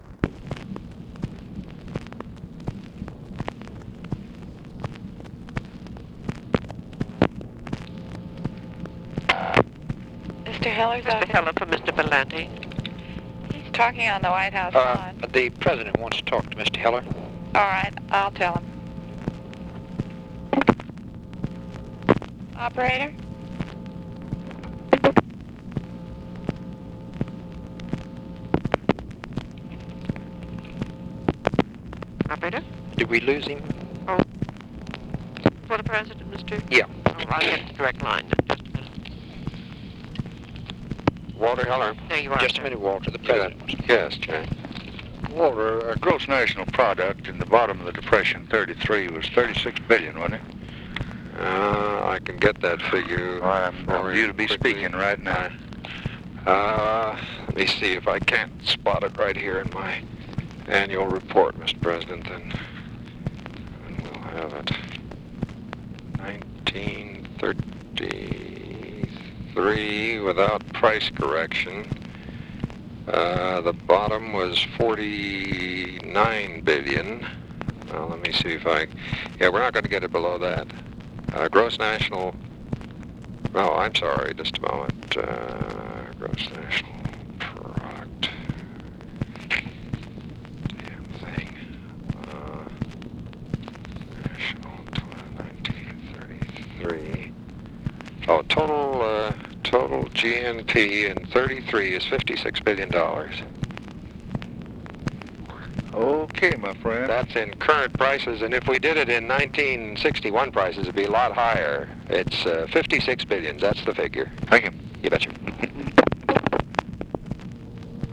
VALENTI PLACES CALL TO HELLER FOR LBJ; OPERATOR TELLS VALENTI SHE WILL PLACE CALL ON DIRECT LINE; LBJ ASKS HELLER FOR GNP FIGURES IN 1933 AT DEPTH OF DEPRESSION FOR SPEECH HE IS ABOUT TO GIVE
Secret White House Tapes